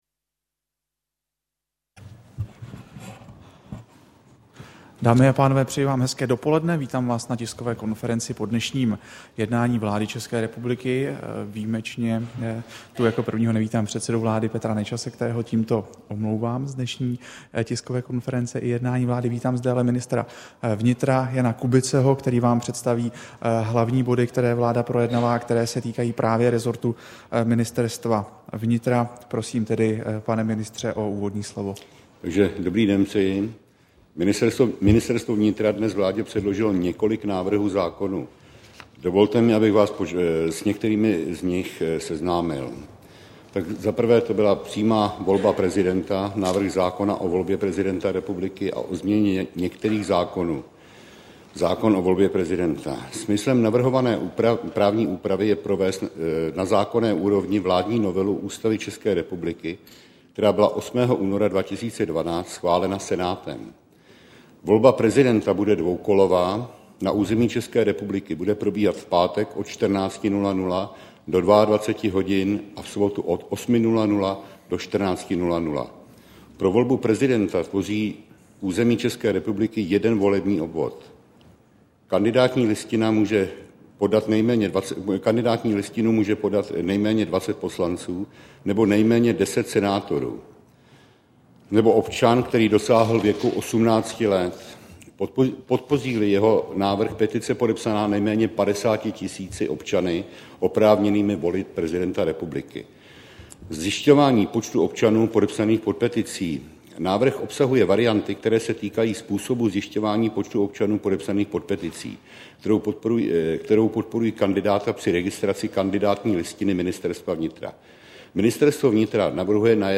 Tisková konference po jednání vlády, 22. února 2012